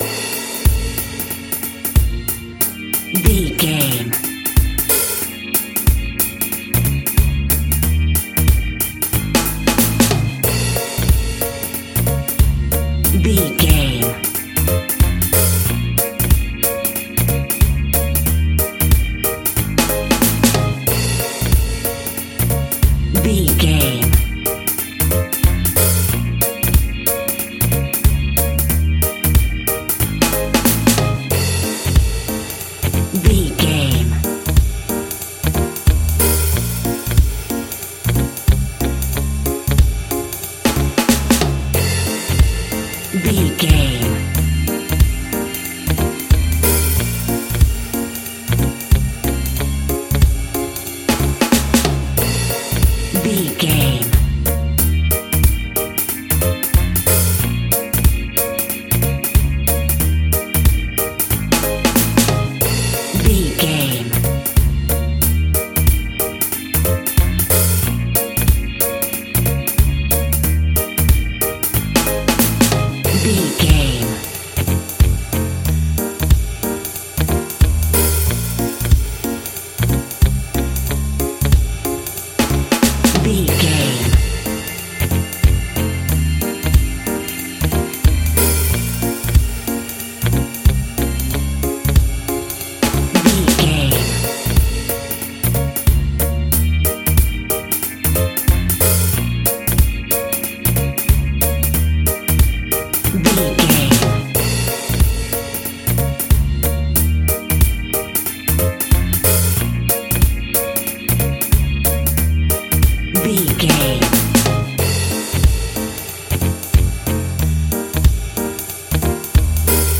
A fast and speedy piece of Reggae music, uptempo and upbeat!
Uplifting
Aeolian/Minor
B♭
reggae instrumentals
laid back
off beat
drums
skank guitar
hammond organ
percussion
horns